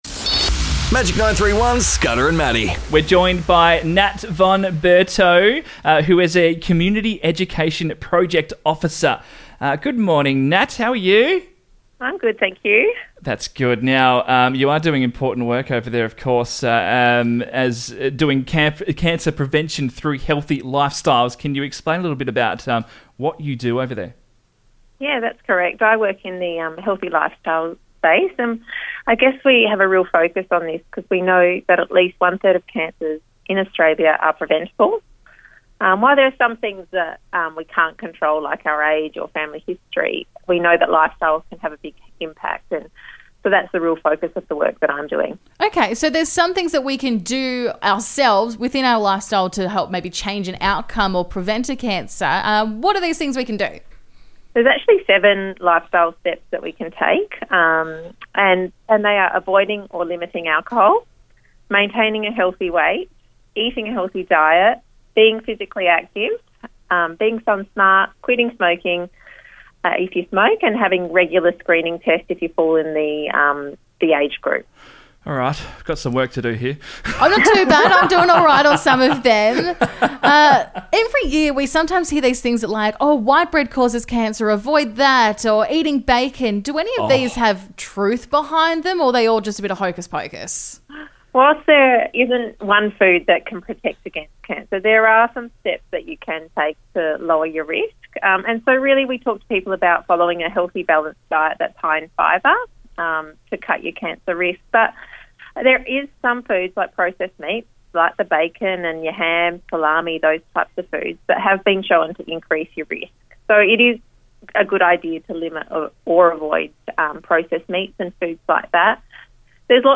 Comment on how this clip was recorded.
As part of our 28k Relay Your Way walk on the weekend, we caught up with members of the Cancer Council SA to chat about what they do.